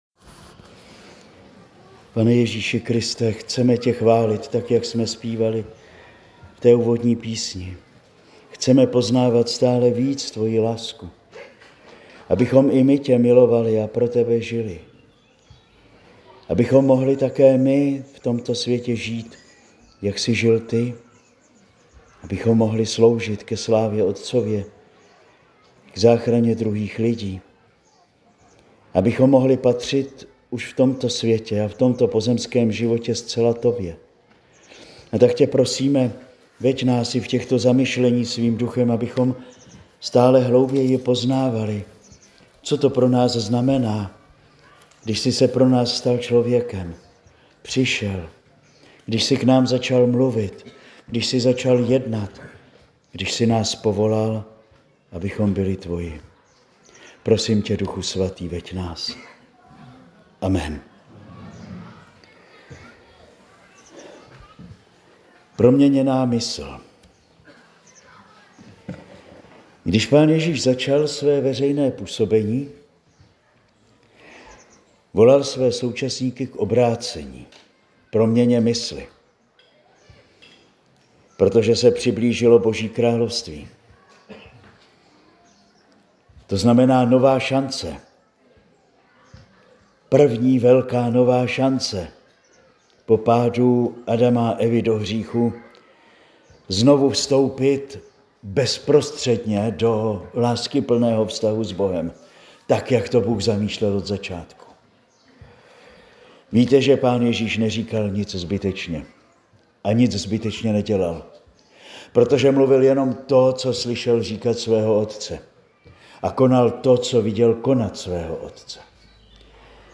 Nyní si můžete poslechnout pátou promluvu.